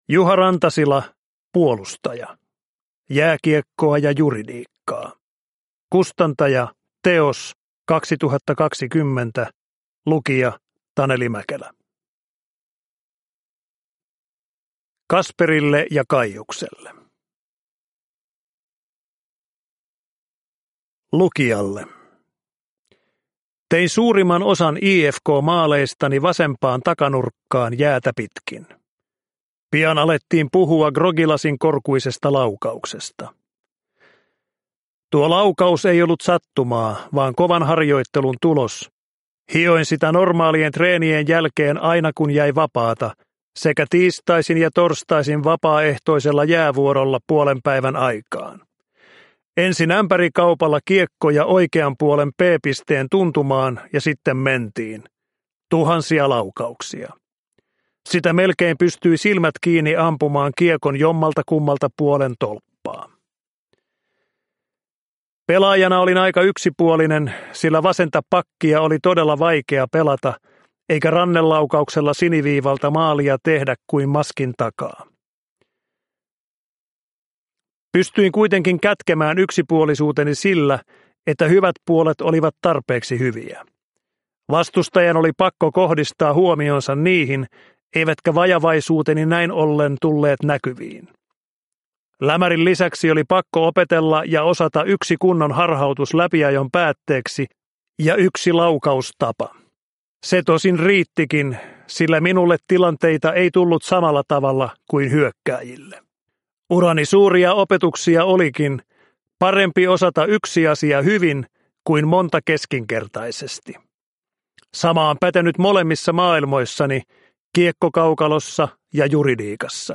Puolustaja – Ljudbok – Laddas ner
Uppläsare: Taneli Mäkelä